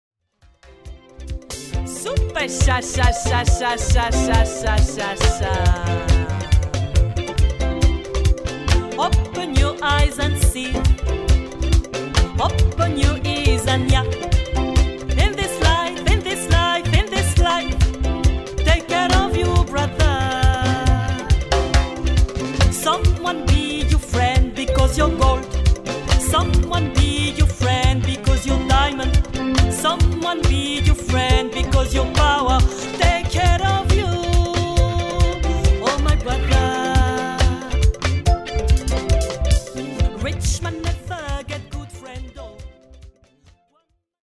une voix qui chante l'Afrique
World Musique
World Music